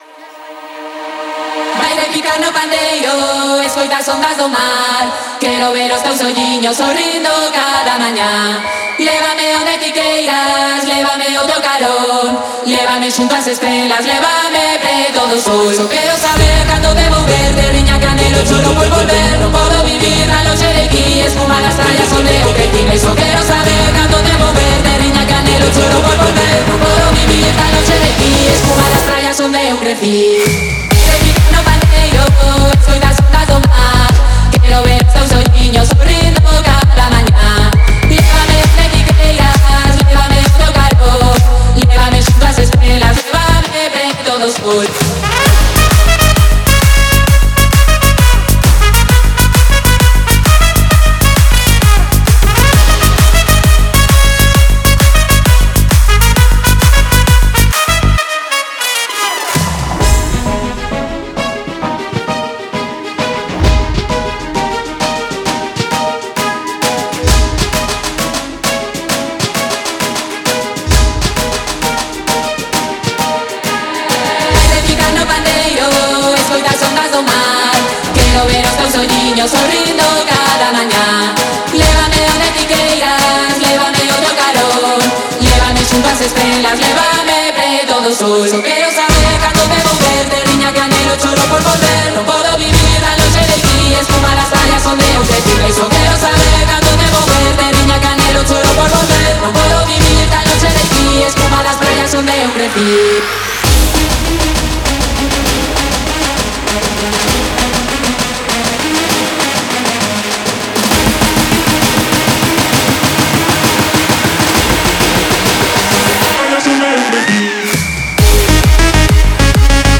• Жанр: EDM, Dance